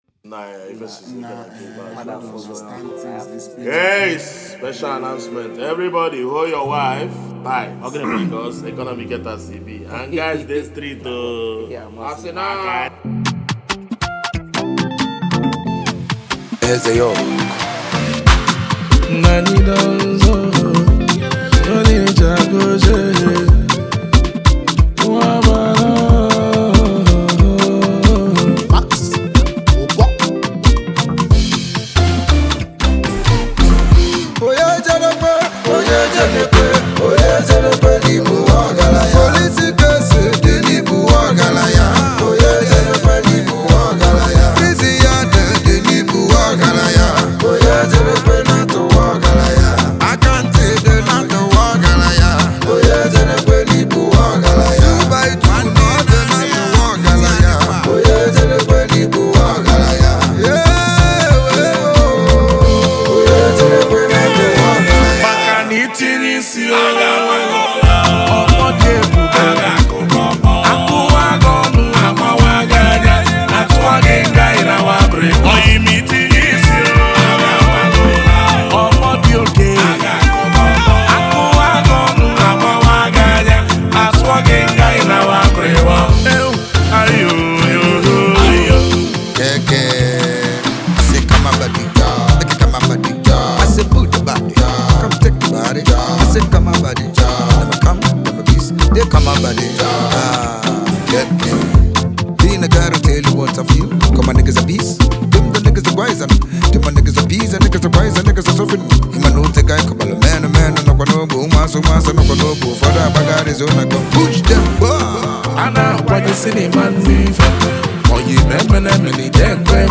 highlife track